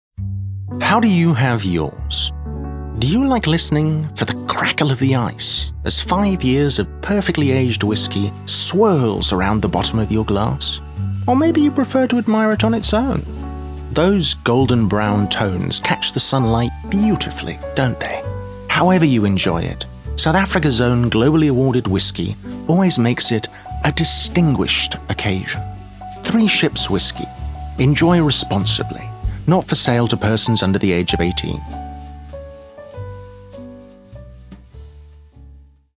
South Africa
clear, crisp, precise, sharp
My demo reels